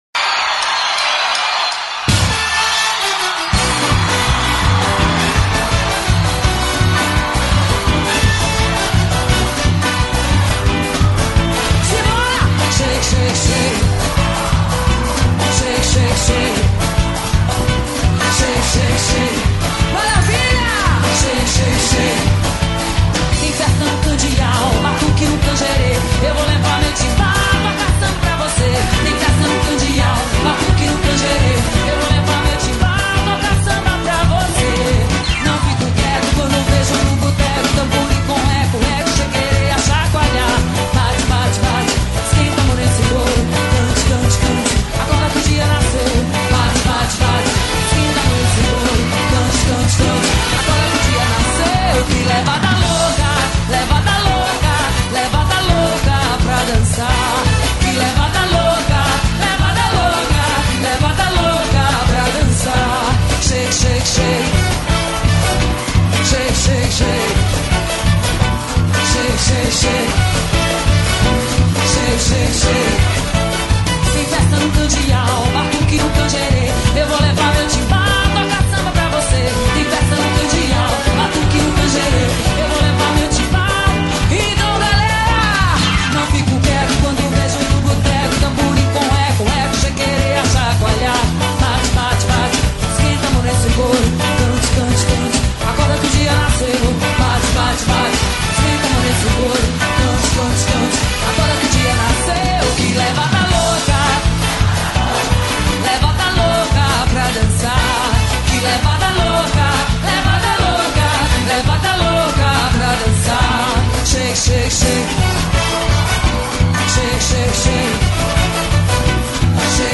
Axe